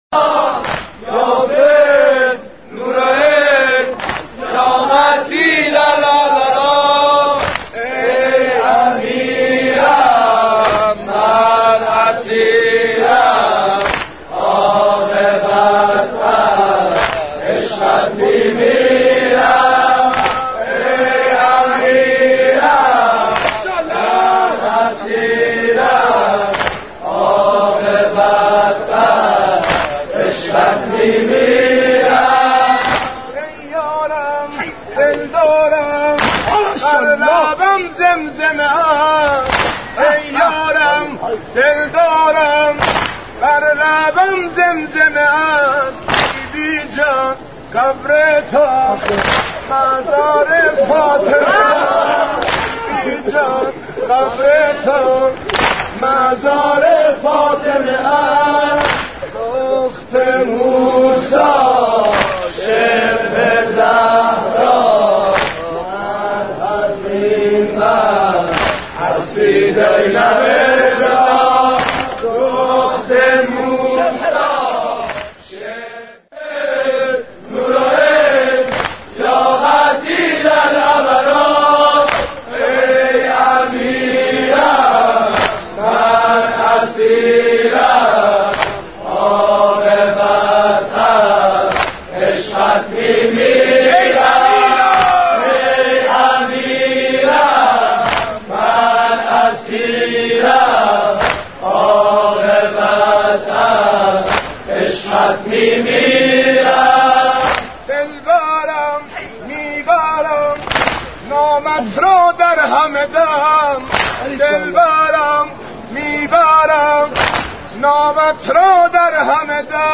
امام حسین ـ واحد 4